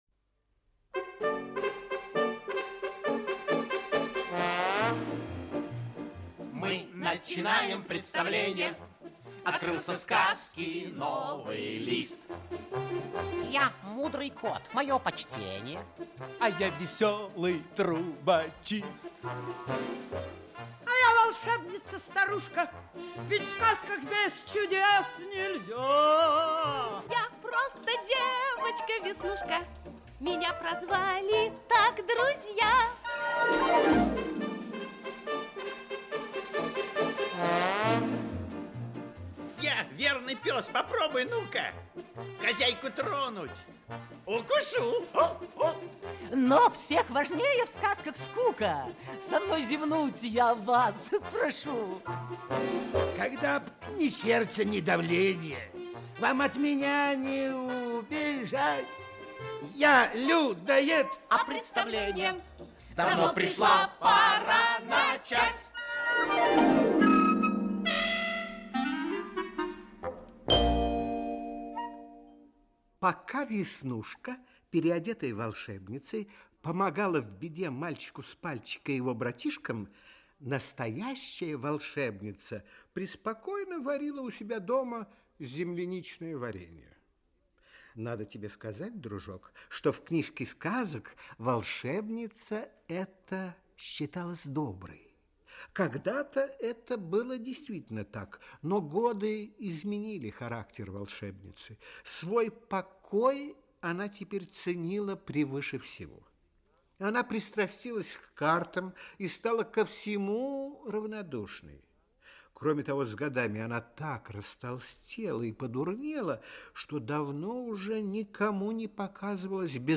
Аудиокнига Счастливый конец. Часть 4 | Библиотека аудиокниг
Часть 4 Автор Екатерина Борисовна Борисова Читает аудиокнигу Актерский коллектив.